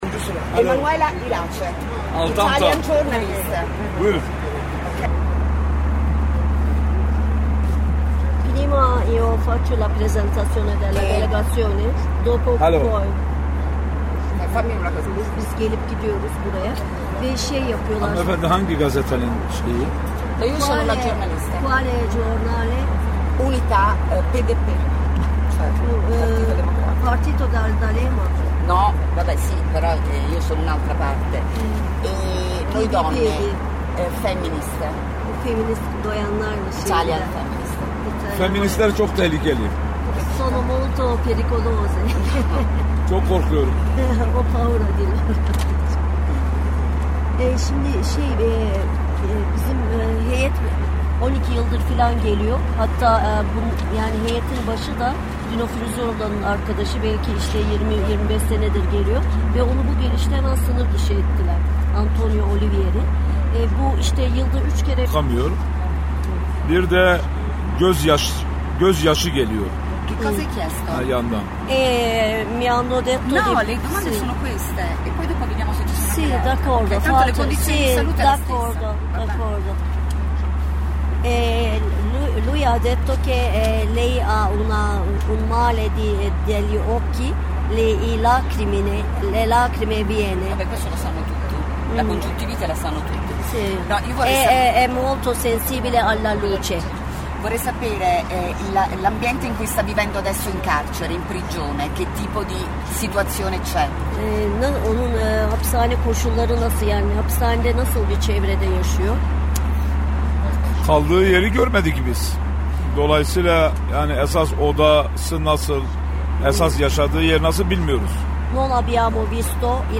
Intervista al deputato curdo Altan Tan
Approfittiamo dell’occasione e chiediamo subito un’intervista.